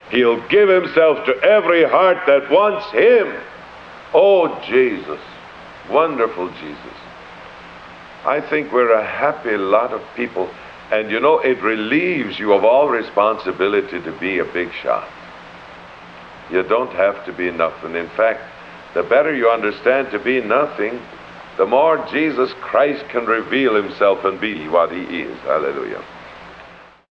Audio Quality: Poor
The DeHummer was set to -30dB, 8 filter, 57.25Hz, and compression, with two additional notch filters at 57.2Hz.  This hiss is so bad that a filter at 4000Hz was added on the vegas track.
A number of stutters and pops were removed, and long pauses shortened.